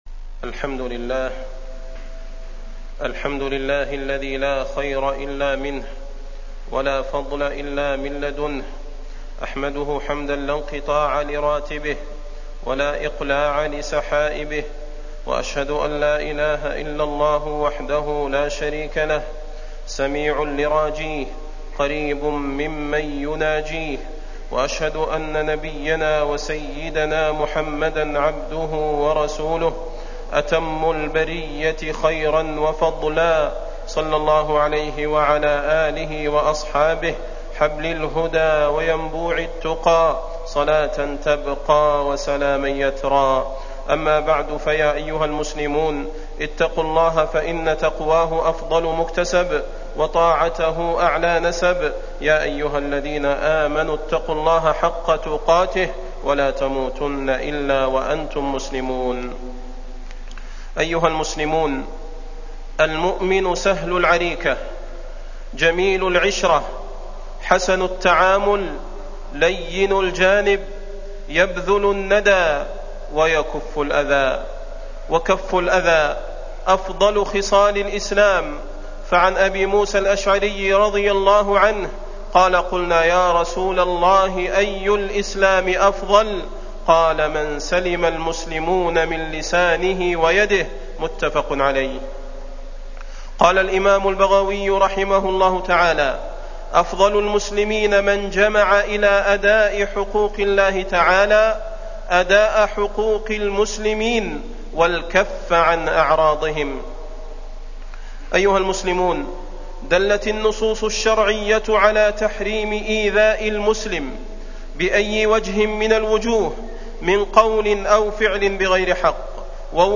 تاريخ النشر ٢٨ ربيع الثاني ١٤٣٠ هـ المكان: المسجد النبوي الشيخ: فضيلة الشيخ د. صلاح بن محمد البدير فضيلة الشيخ د. صلاح بن محمد البدير كف الأذى عن المسلمين